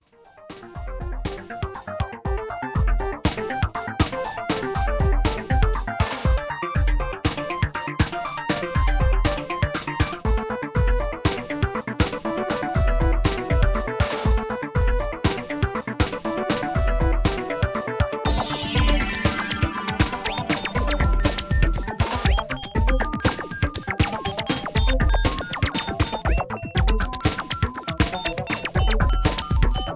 Relaxed Electro Ambient